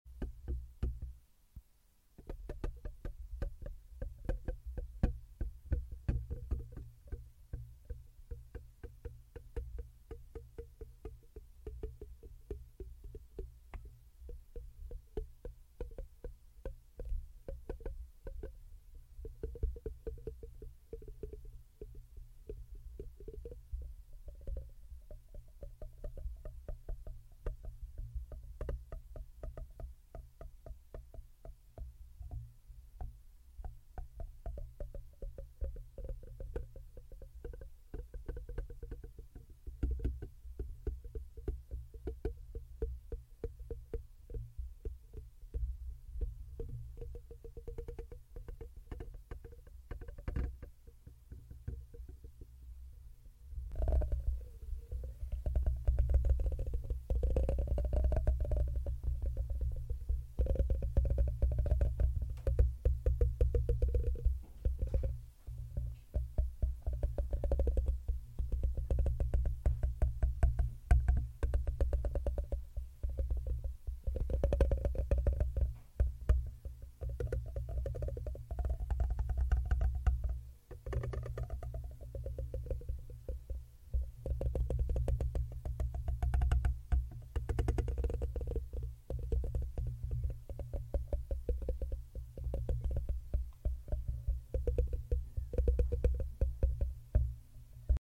ASMR💆🏻‍♀🧠BRAIN PENETRATION 🧠 SLEEP IMMEDIATELY